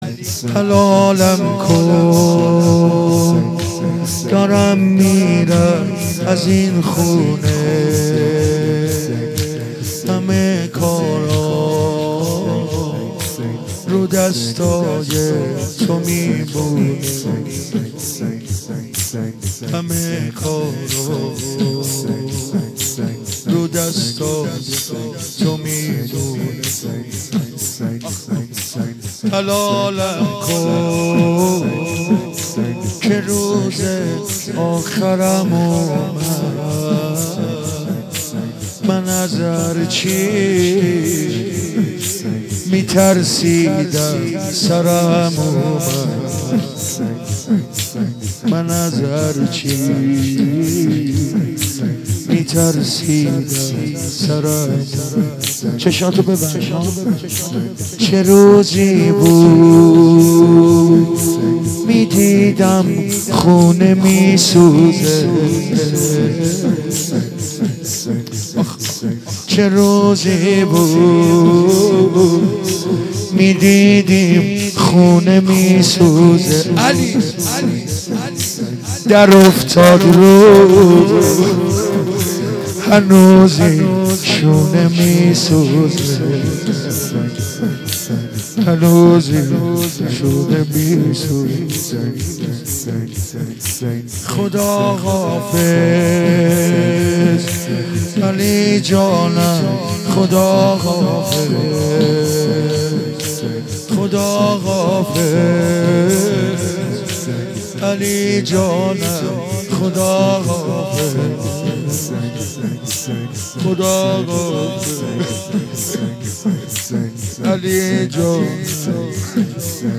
جلسه هفتگی دوشنبه ٢۵ دی ماه ١٣٩۶